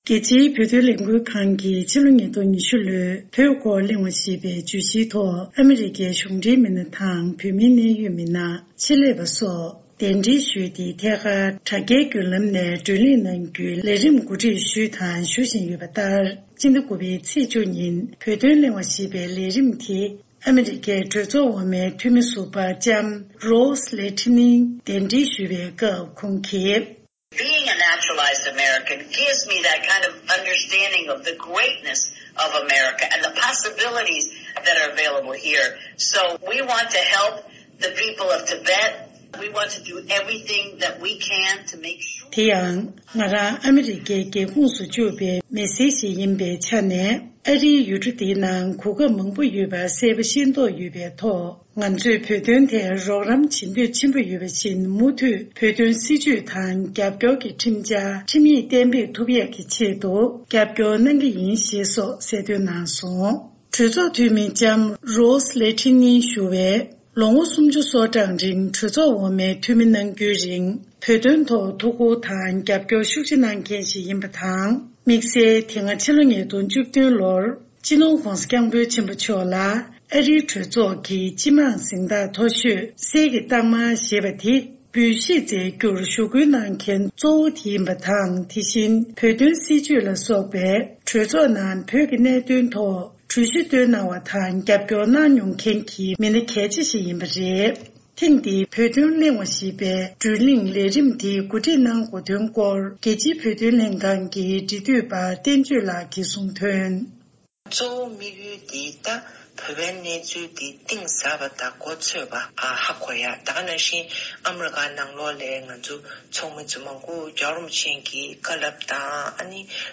གནས་ཚུལ་ཕྱོགས་སྒྲིག་དང་སྙན་སྒྲོན་ཞུས་པར་གསན་རོགས་ཞུ།།